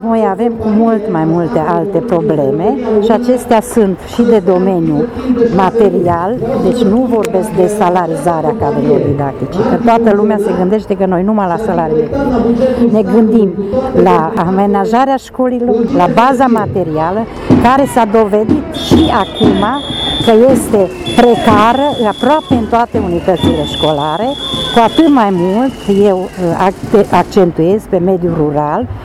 Zeci de sindicaliști au protestat astăzi în fața Prefecturii Mureș